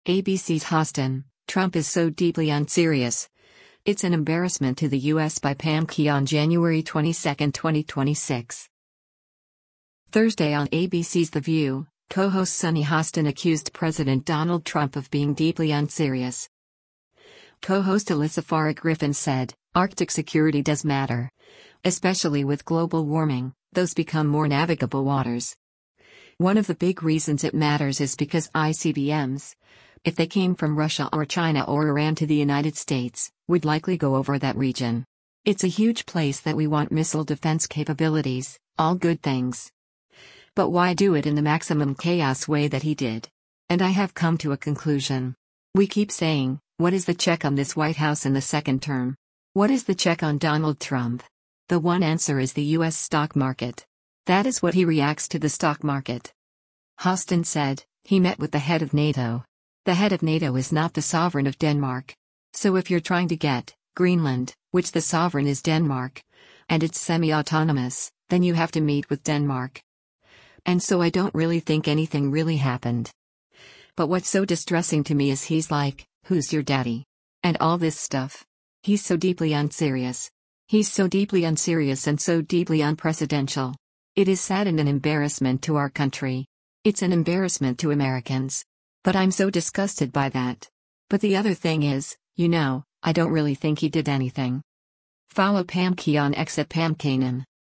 Thursday on ABC’s “The View,” co-host Sunny Hostin accused President Donald Trump of being “deeply unserious.”